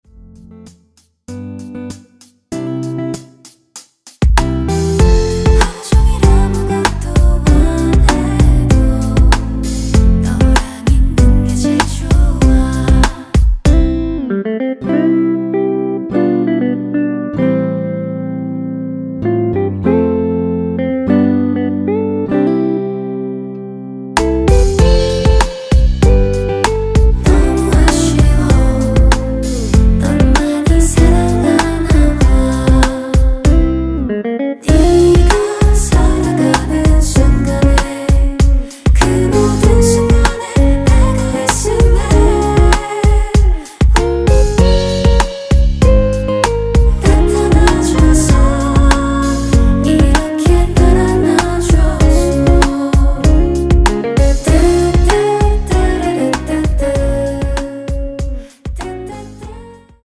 키 C 가수